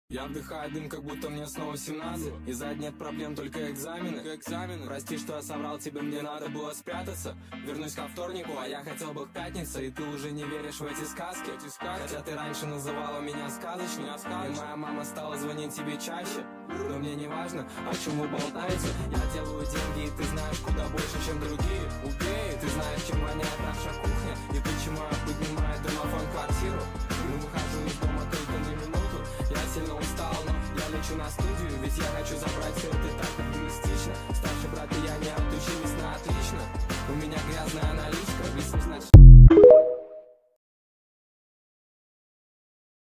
TikTok Remix Version